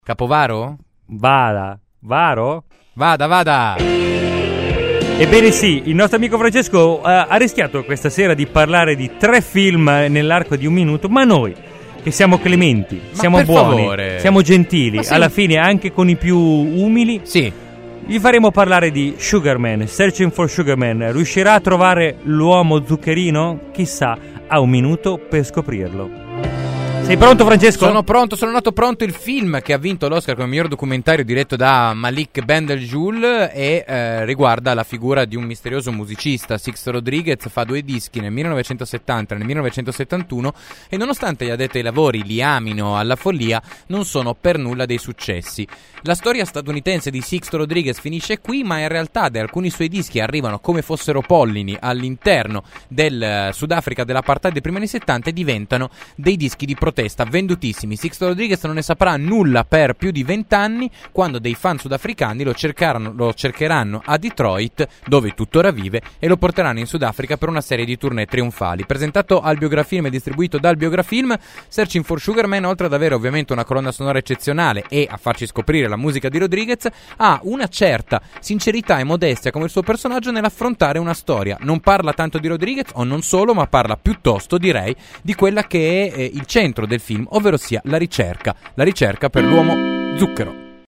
“Dammi solo un minuto” è una rubrica di Seconda Visione. Durante la puntata viene chiesto agli spettatori di scegliere (via mail o sms) uno dei conduttori per fargli riassumere in un minuto trama e giudizio di uno dei film in scaletta.